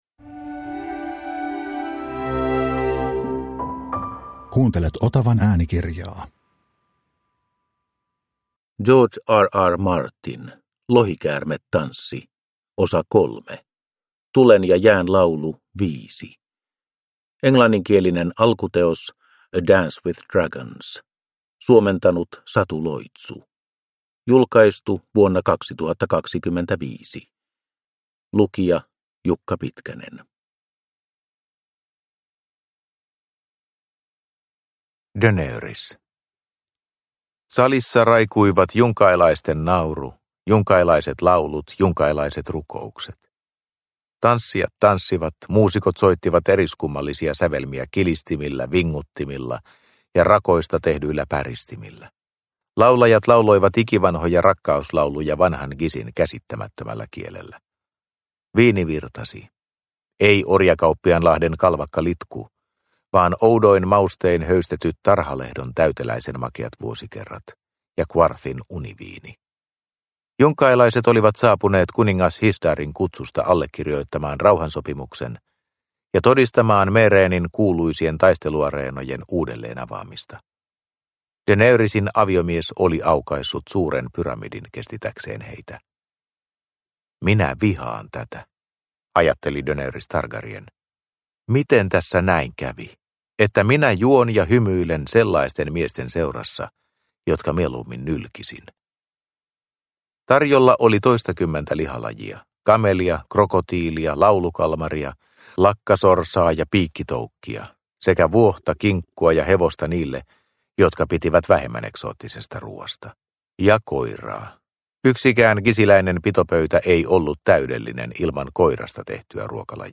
Lohikäärmetanssi 3 – Ljudbok